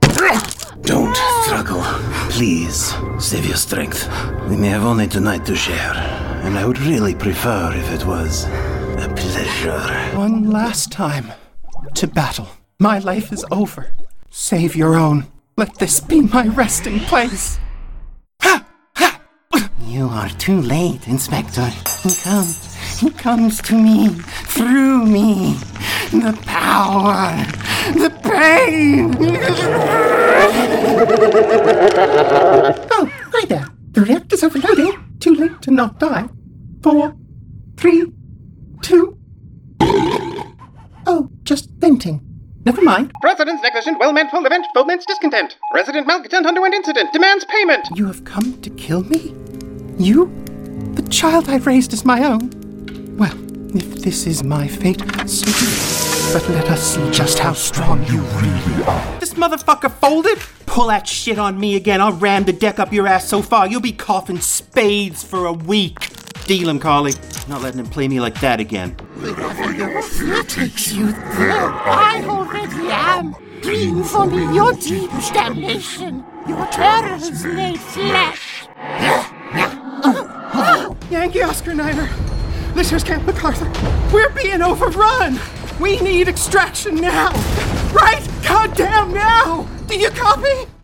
Video Game VO Demo